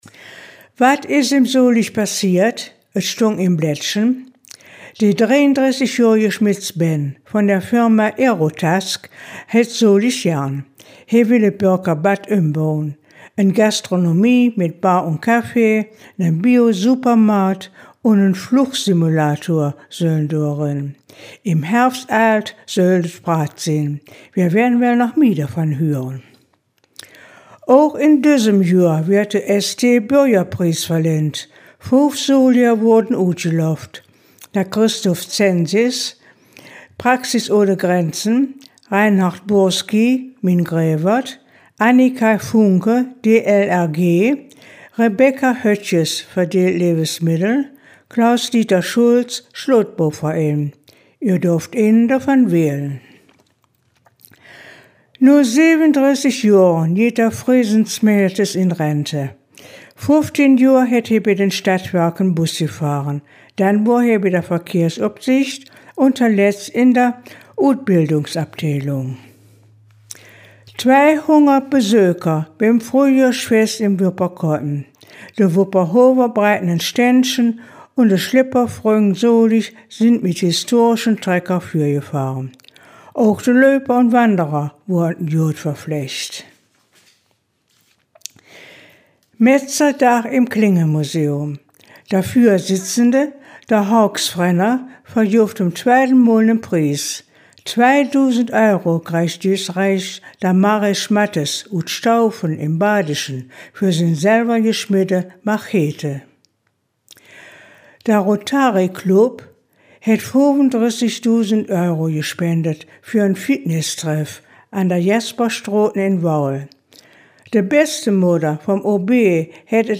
Der Nachrichten-Rückblick auf die KW20
soliger-platt-news-18kw20.mp3